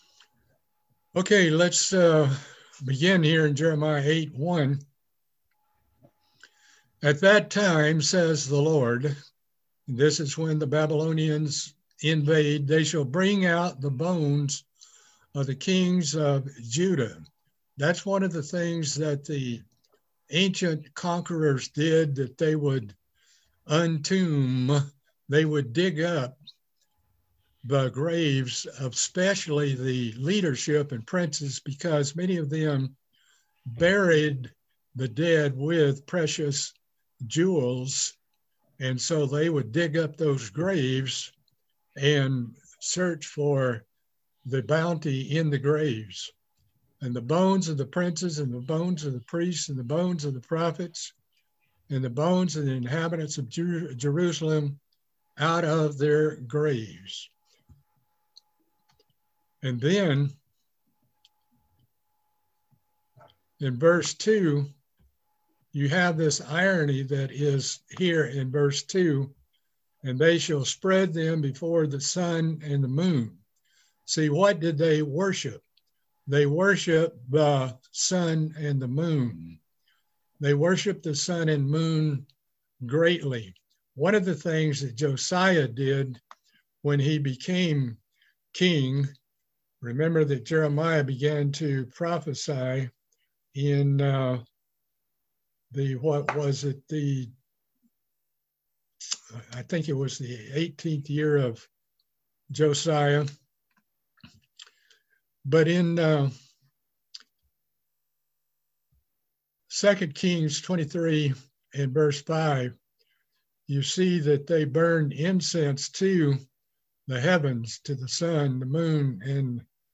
Part 6 of a Bible Study series on the book of Jeremiah.